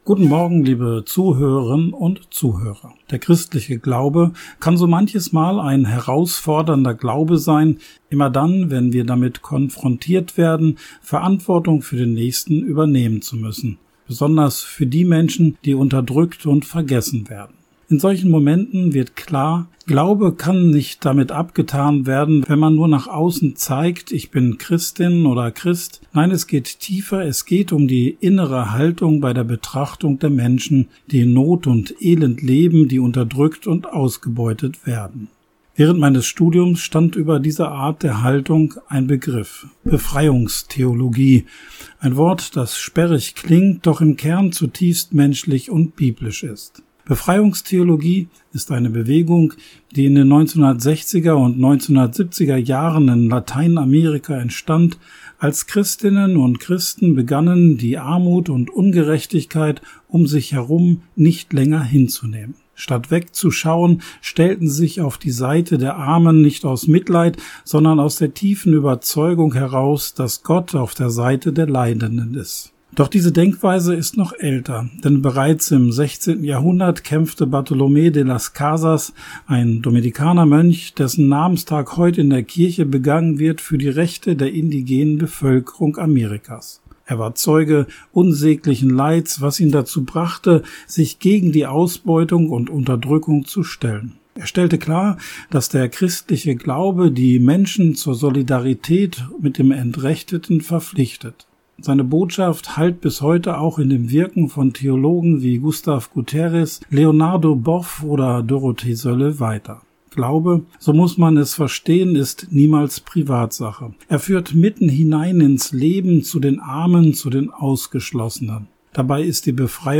Radioandacht vom 31. Juli